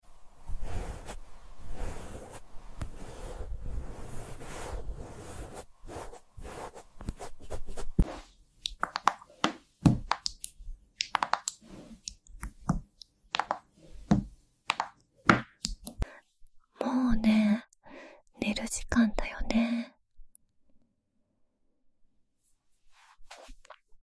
音だけを、そっと届ける夜。 ブラッシング、タッピング、そして最後にささやき。 「もう、寝る時間だよ…」 目でも、耳でも、癒されて。 #A#ASMR